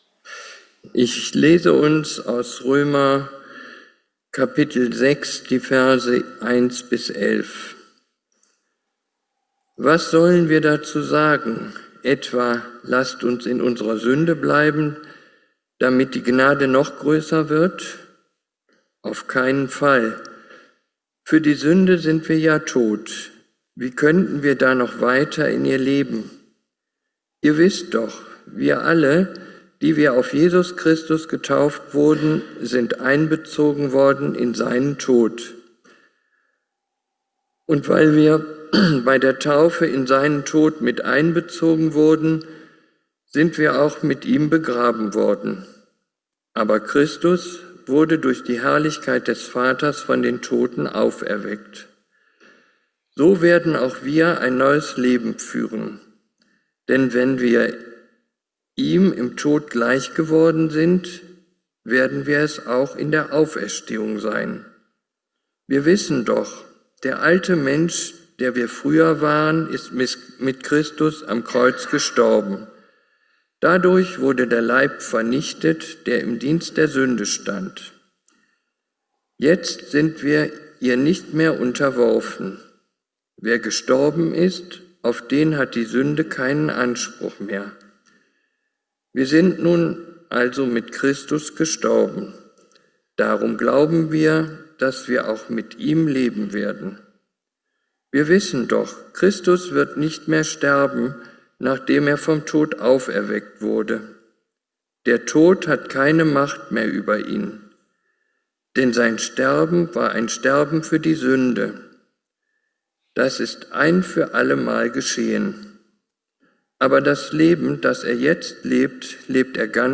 Spannend und eine sehr persönliche Predigt.